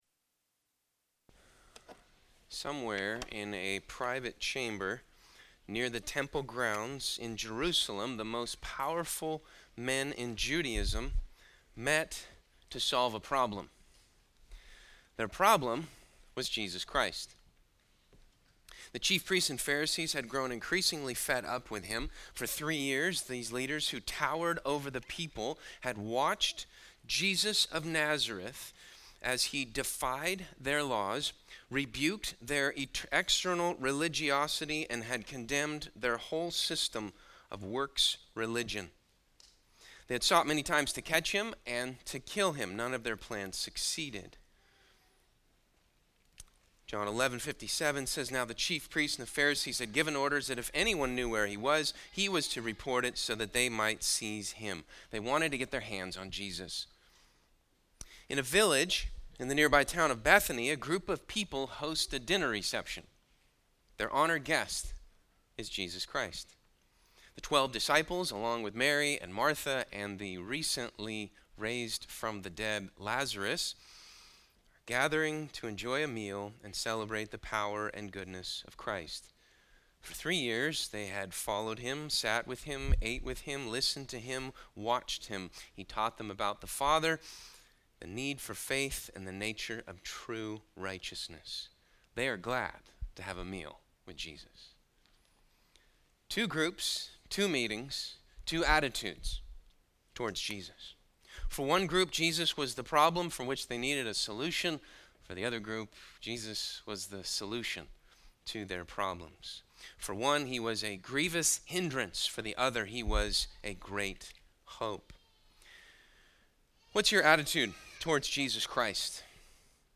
From Series: "All Sermons"